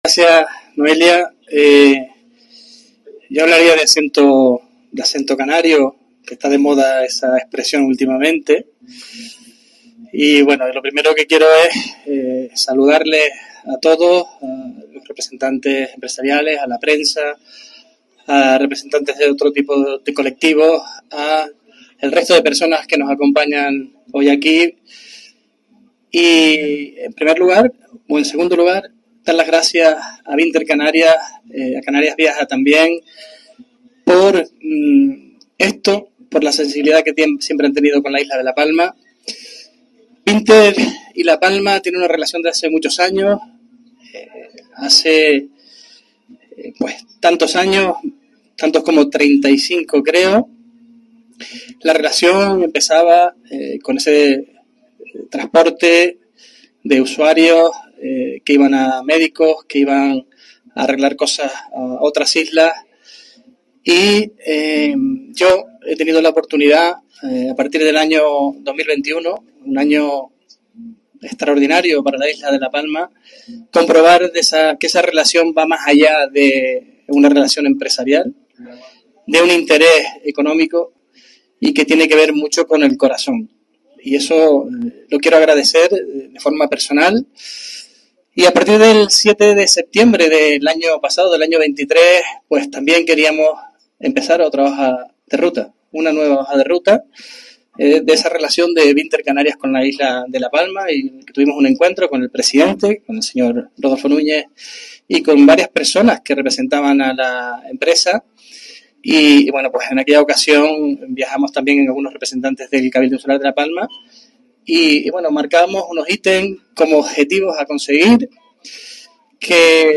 Declaraciones Sergio Rodríguez Binter.mp3